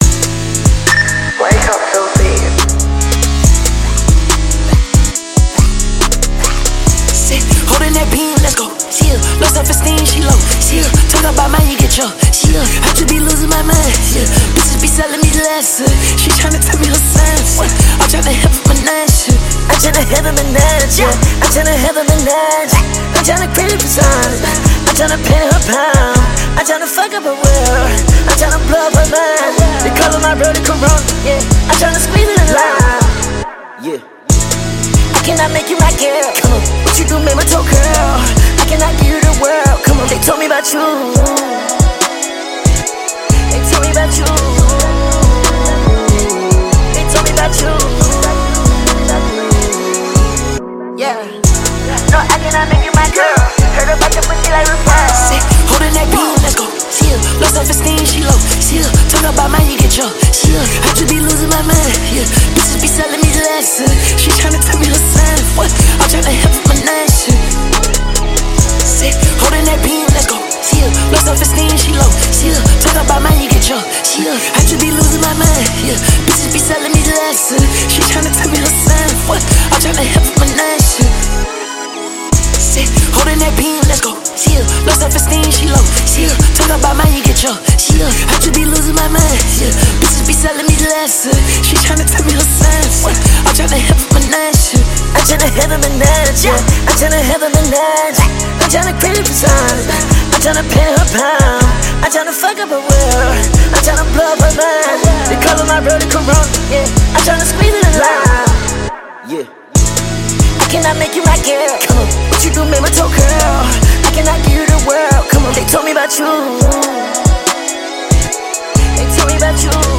دانلود آهنگ سبک هیپ هاپ